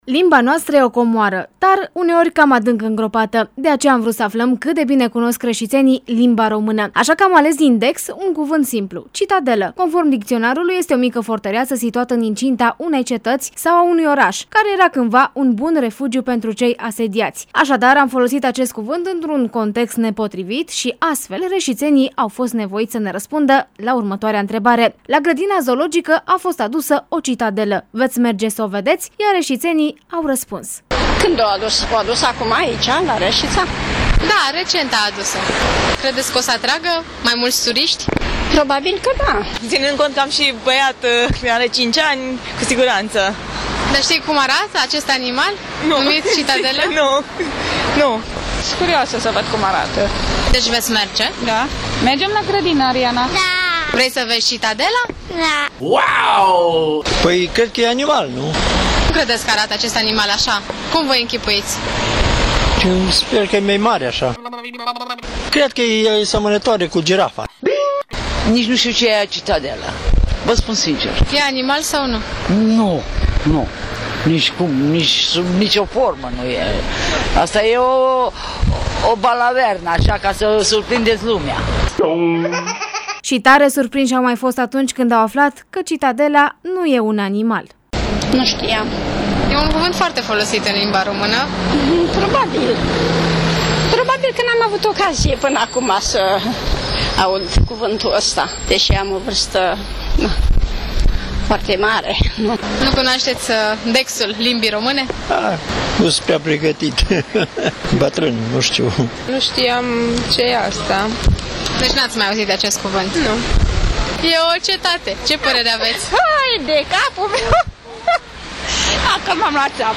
Aşadar, am folosit acest cuvant într-un context nepotrivit şi astfel reşiţenii au fost nevoiţi să ne răspundă la întrebarea: “La grădina zoologică a fost adusă o citadelă, veţi merge să o vedeţi?”, iar reşiţenii au raspuns: